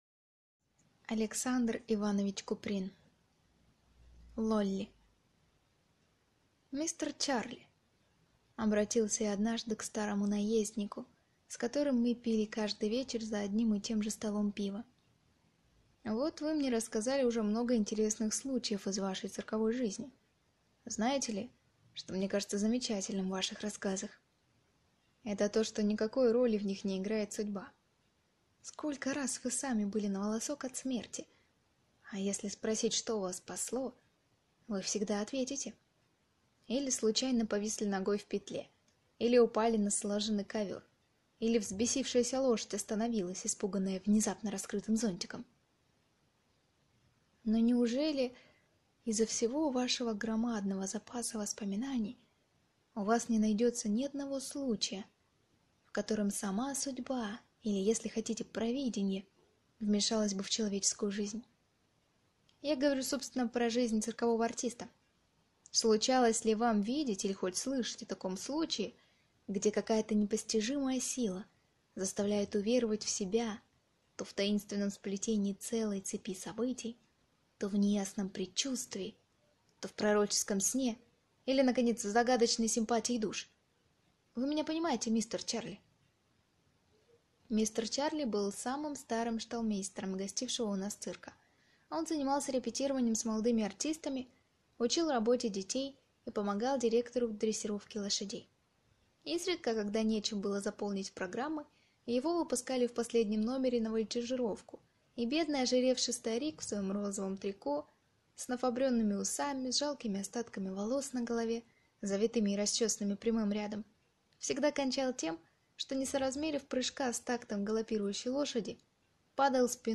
Аудиокнига Лолли | Библиотека аудиокниг